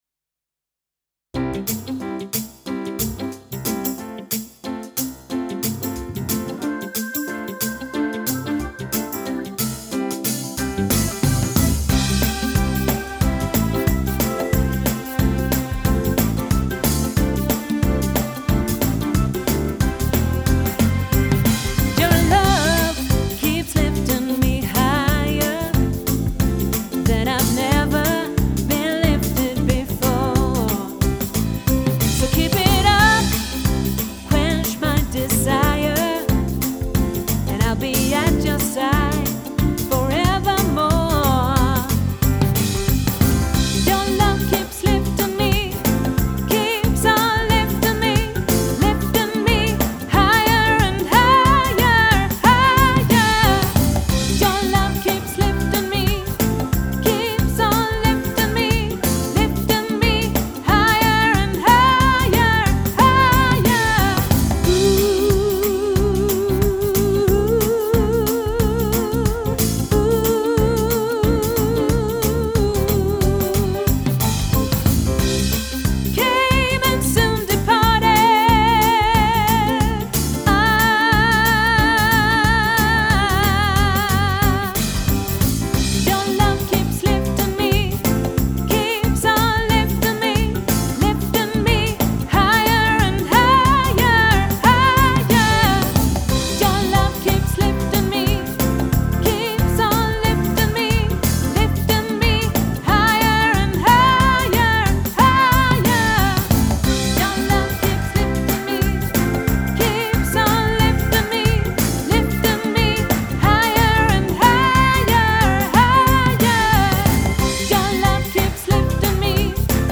Gospeldag i Falköping lörd 5 okt 2019
Higher_and_higher-Sopran.mp3